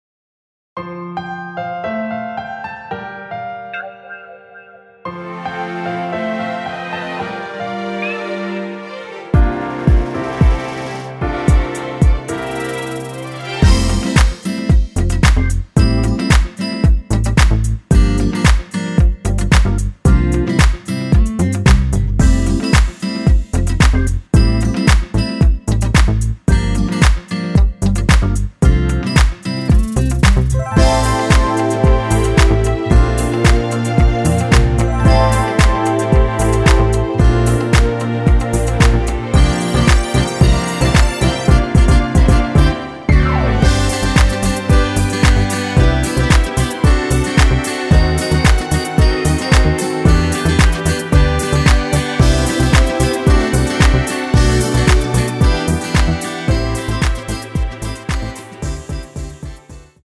(-1) 내린 MR 입니다.(미리듣기 참조)
◈ 곡명 옆 (-1)은 반음 내림, (+1)은 반음 올림 입니다.
앞부분30초, 뒷부분30초씩 편집해서 올려 드리고 있습니다.